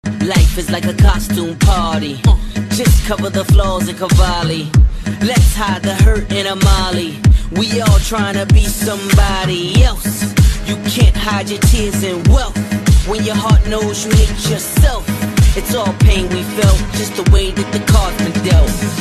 synth and electropop styles